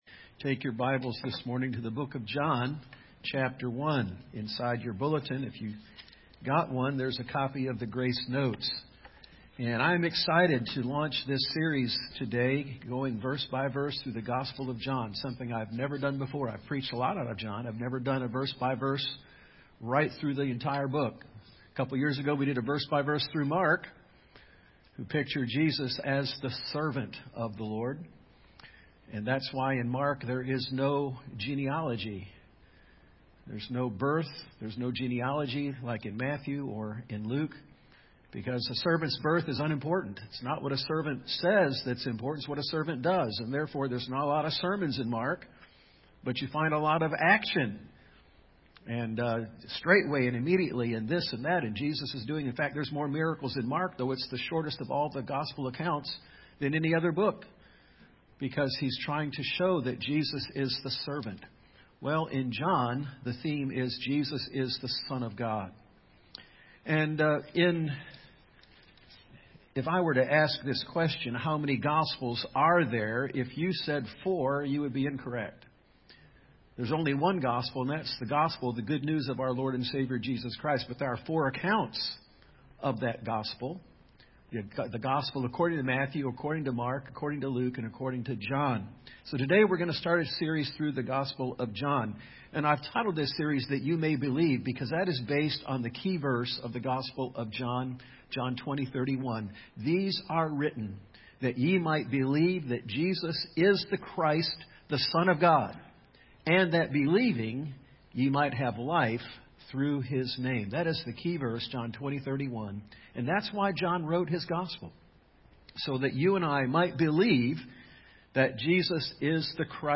John 1:1-3 Service Type: AM Topics: Belief , Names of Jesus , Son God , Truths about Jesus « What Does Looking unto Jesus Look Like?